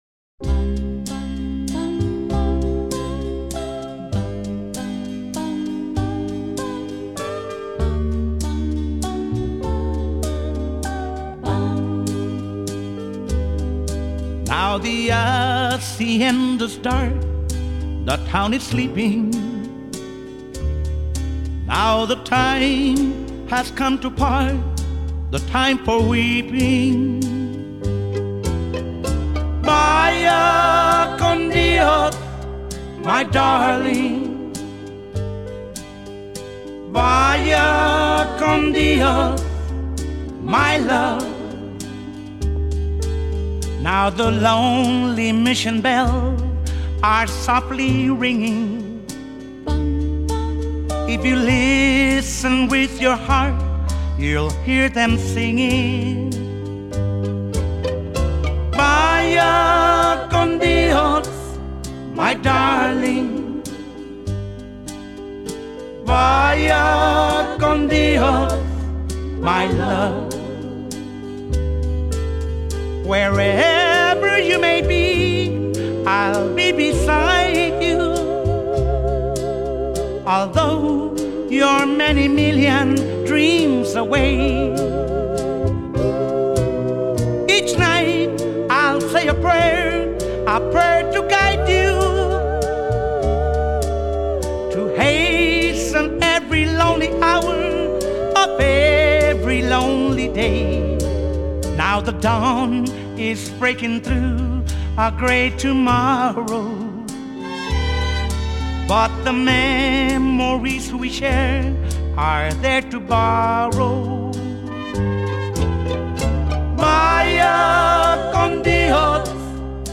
Legendary Tex-Mex singer/songwriter